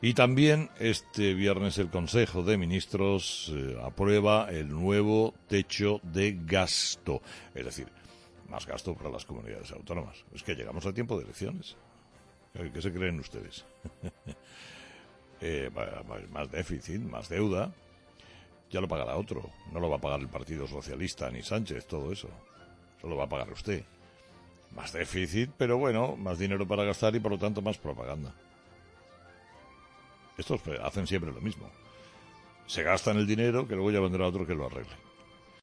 En su monólogo de este viernes, Carlos Herrera ha tratado este asunto y ha advertido a Sánchez del peligro que supone volver a la senda del descontrol del déficit.